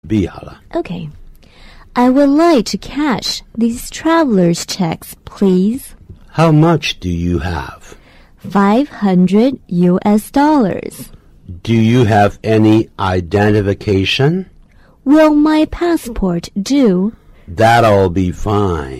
dialogue A